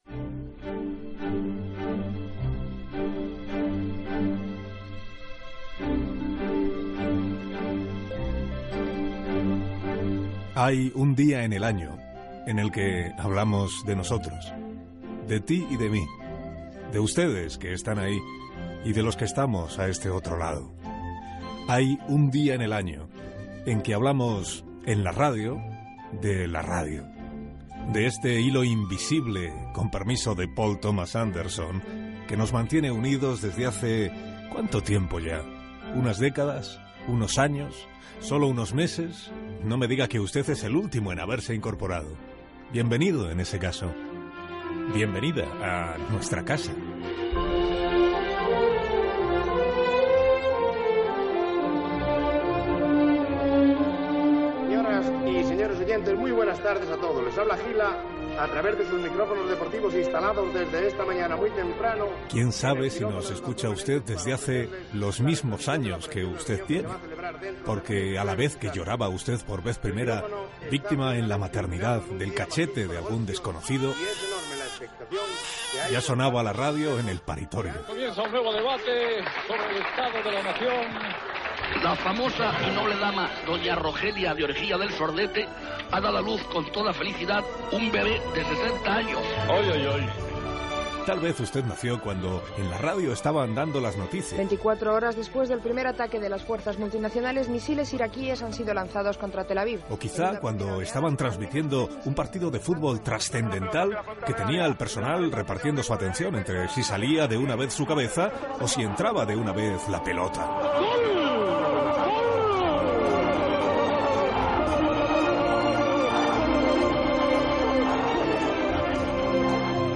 La ràdio segons Carlos Alsina en el deu Dia Mundial Gènere radiofònic Info-entreteniment